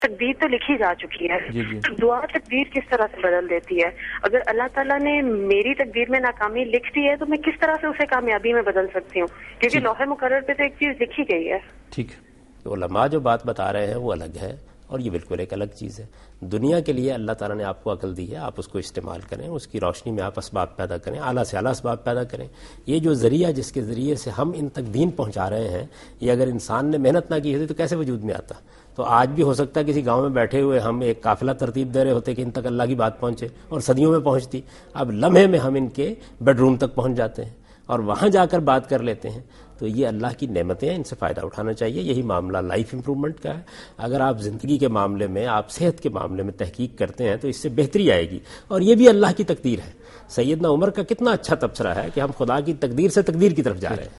Answer to a Question by Javed Ahmad Ghamidi during a talk show "Deen o Danish" on Duny News TV